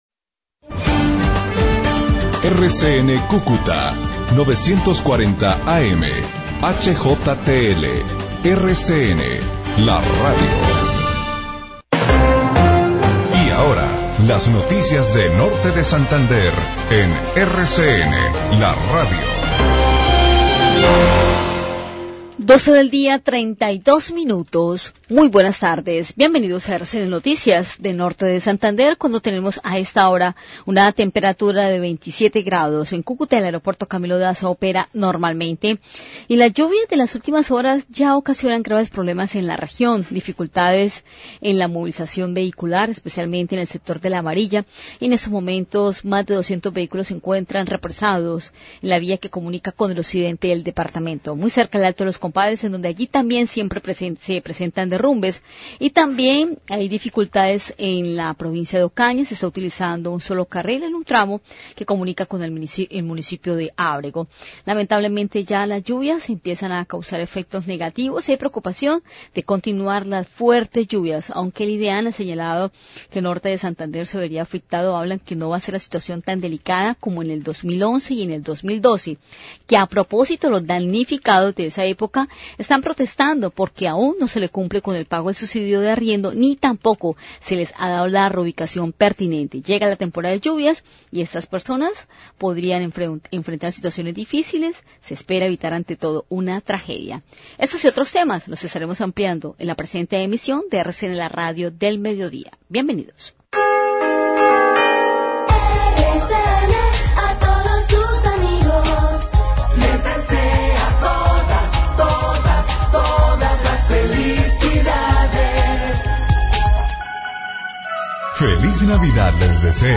[Audio] RCN noticias de Cúcuta y NdeS Co:Mar 05Nov2013| RFN Noticias!